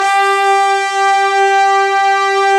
Index of /90_sSampleCDs/Roland LCDP06 Brass Sections/BRS_Fat Section/BRS_Fat Pop Sect